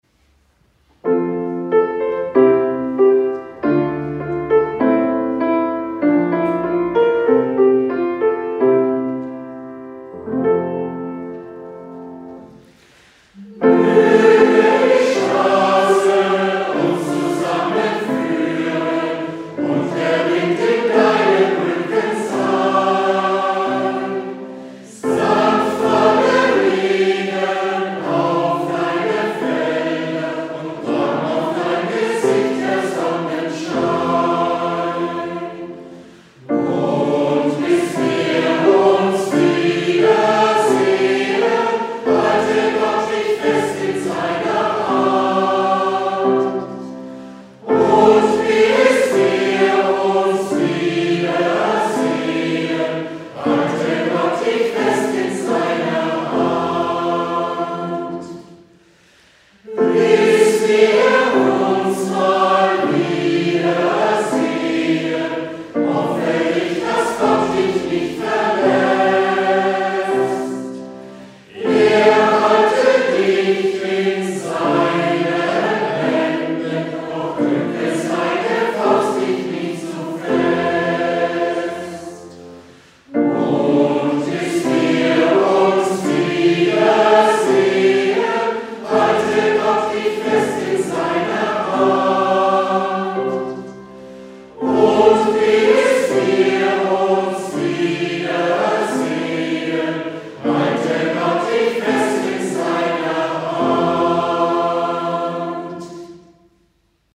Unsere Auftritte aktuell